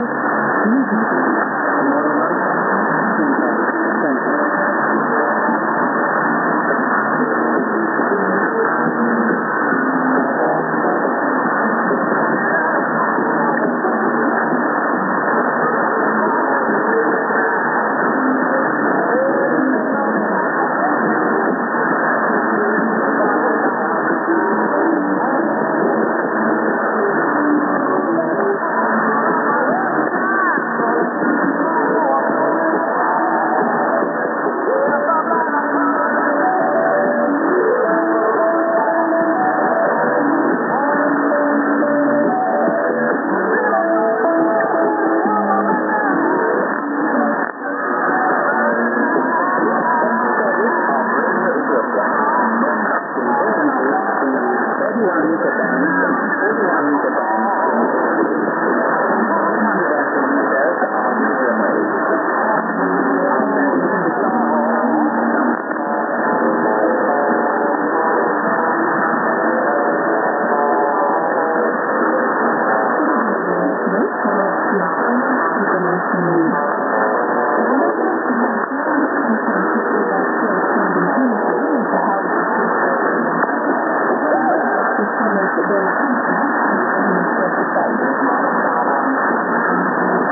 Eng. End->Urdu St. ->ID+SKJ(man)->|->ID+SKJ(man+women)->　＊00'35":IS(R.Ethiopia - EX Serv.)